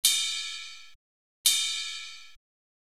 Cymbal.wav